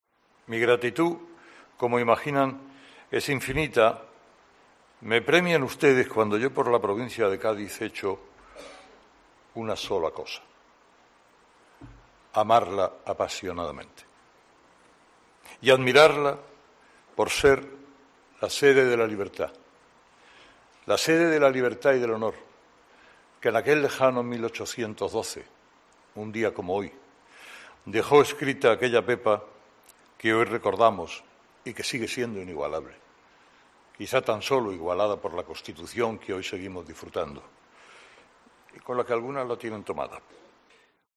El discurso de Carlos Herrera como Hijo Predilecto de la Provincia de Cádiz